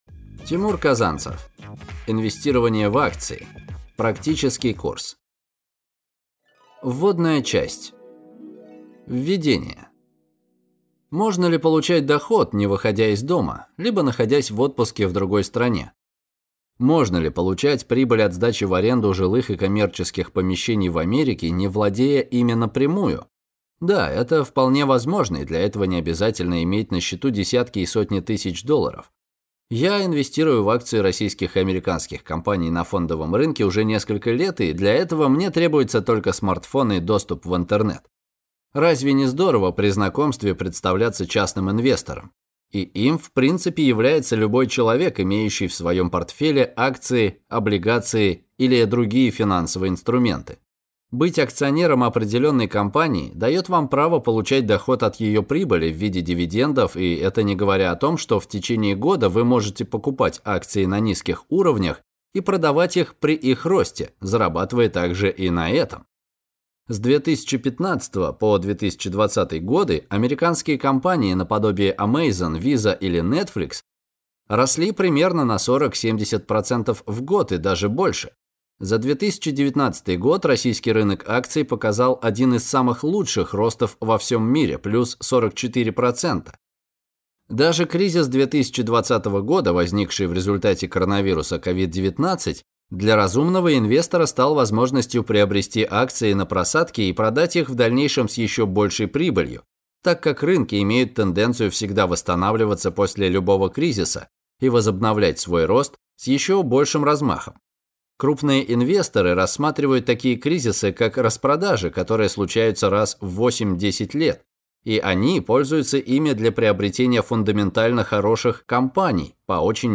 Аудиокнига Инвестирование в акции. Практический курс | Библиотека аудиокниг